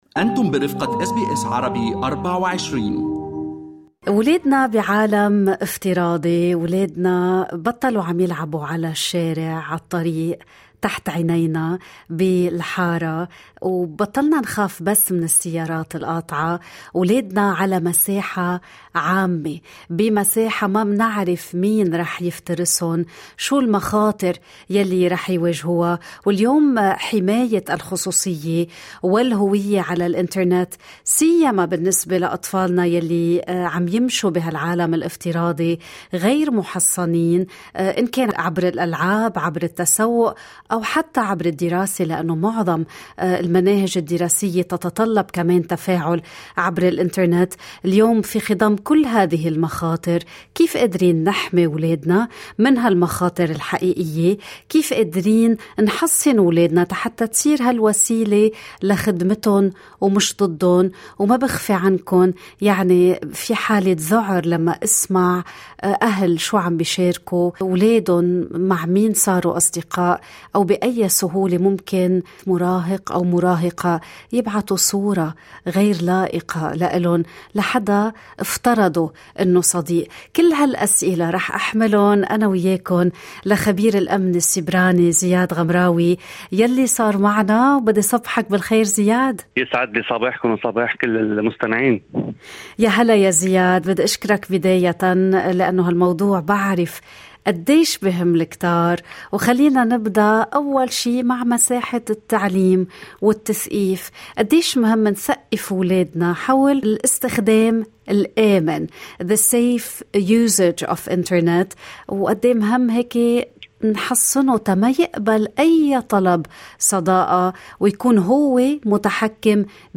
تحدث خبير الأمن السيبراني ضمن برنامج "صباح الخير استراليا" عن طرق فعّالة لضمان حماية الخصوصية للطفل بدءًا من خلق مساحة تثقيفية تحصّن الأهل كما الأولاد في وجه أي اعتداء سيبراني.